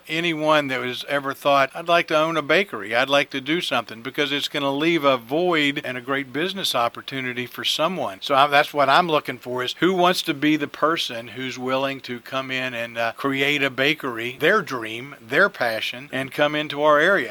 Mayor Ray Morriss spoke with WCBC News regarding the number of businesses closing in the downtown Cumberland and LaVale areas.